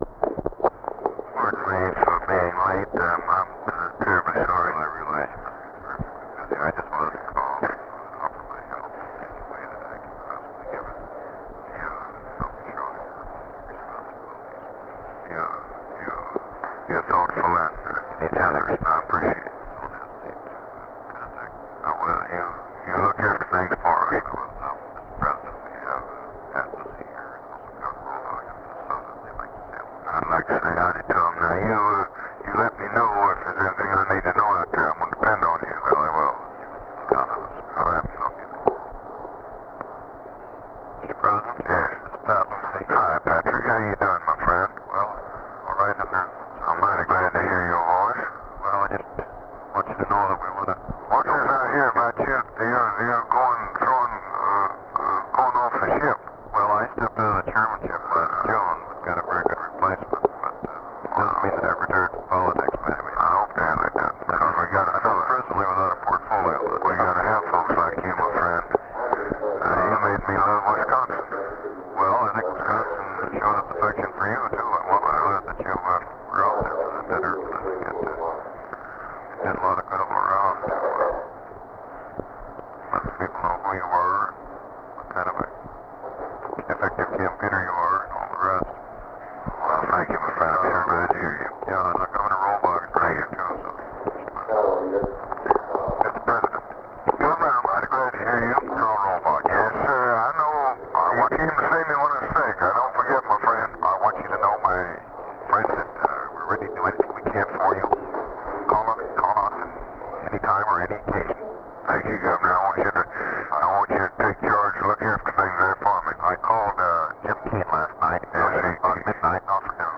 Conversation with JOHN REYNOLDS, November 23, 1963
Secret White House Tapes